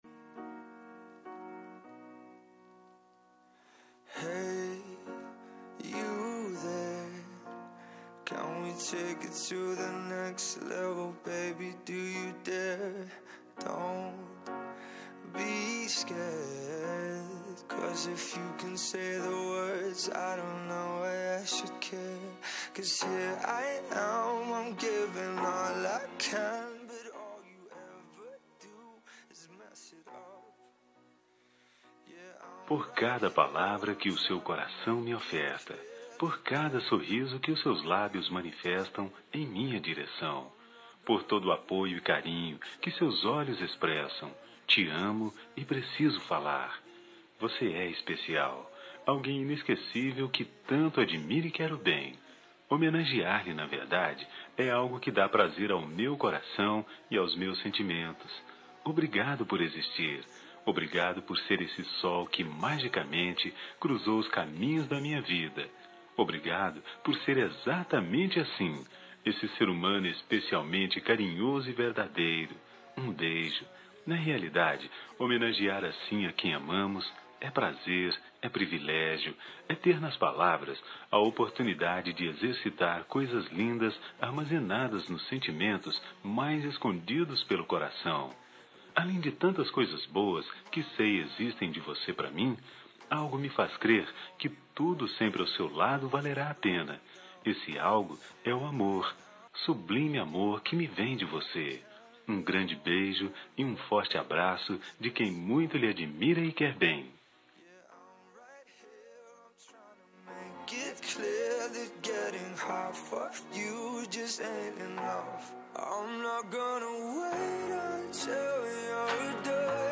Dia das Mulheres Para Namorada – Voz Masculina – Cód: 5311 – Linda